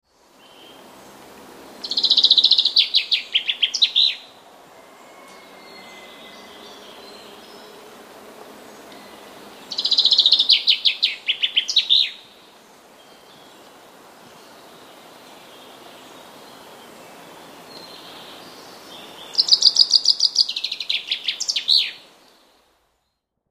- FRINGILLA COELEBS
Natomiast trzecia piosenka jest inna, złożona z innych sylab, taki sam jest tylko zawijas. Występują w niej sylaby dość długie, które śpiewane są w wolniejszym tempie oraz sylaby krótkie, śpiewane szybciej.
Dodam jeszcze, że po żadnej piosence nie ma kropki. Aby to sprawdzić, Ten przykład przedstawiam w 4-krotnym zwolnieniu i obniżeniu o dwie oktawy.